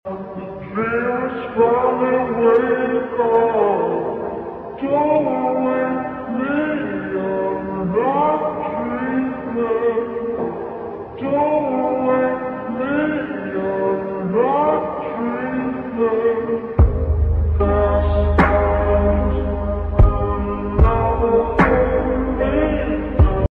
Lo fi filter